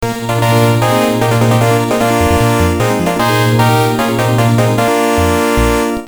ウエーブテーブル音源の音の例